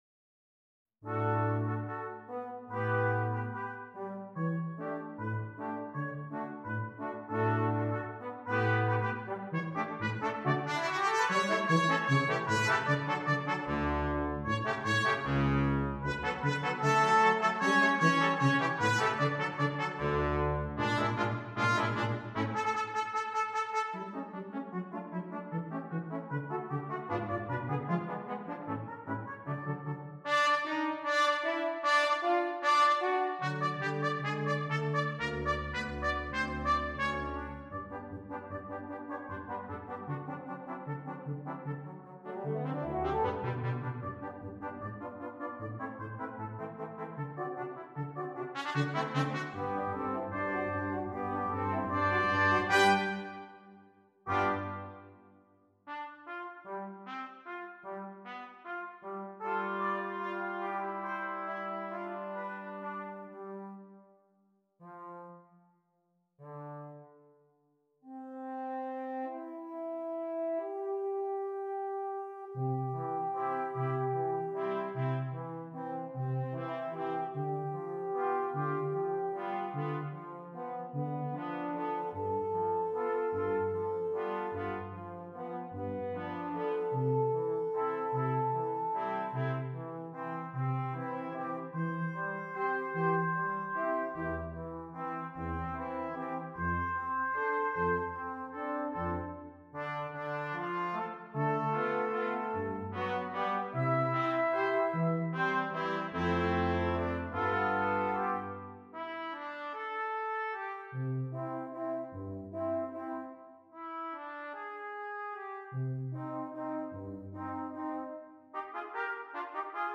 Brass Quintet
beautiful waltzes